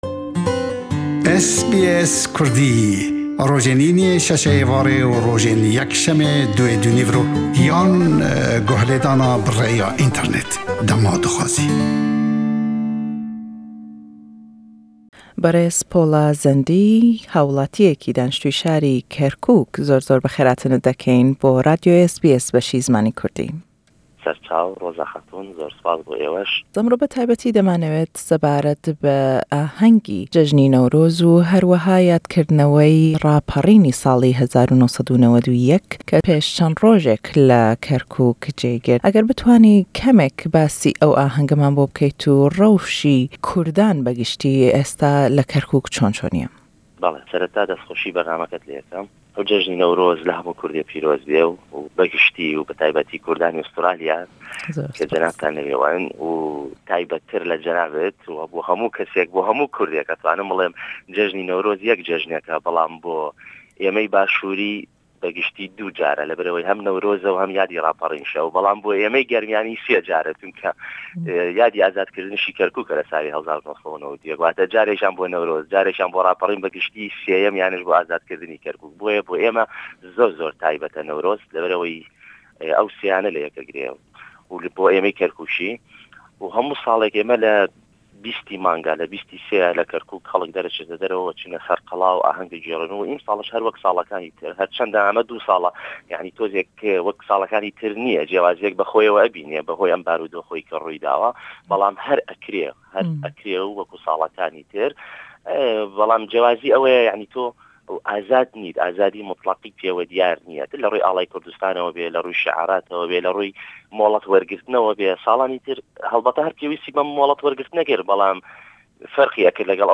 Le em lêdwane da pirsiyaryan lêdekeyn sebaret be ahengî Newrozî em sall le şarî Kerkûk û webirhênanewey raperrîn û rizgar kirdinî Kerkûk le 1991. Aye êsta barûdoxî ew şare çone bo hawwillatîyanî Kurd paş 16y Oktoberî 2017...?